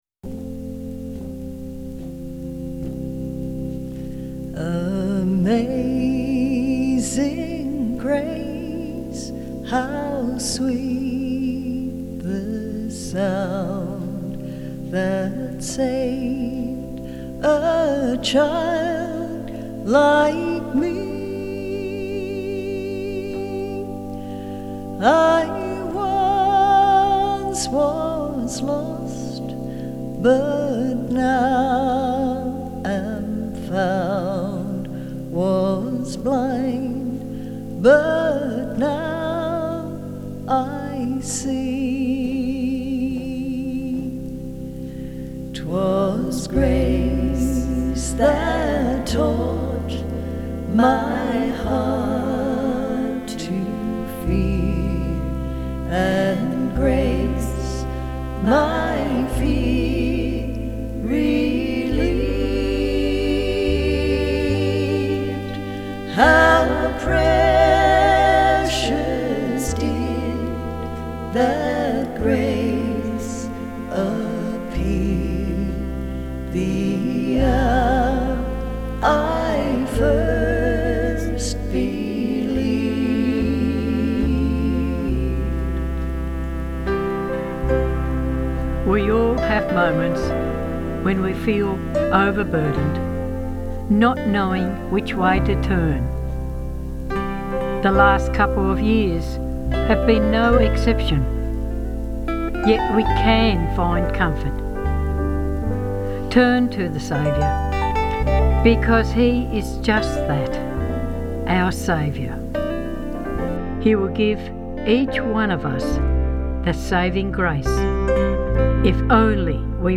heartfelt arrangement
Australia’s Queen of the Country Piano
Country Guitar
Traditional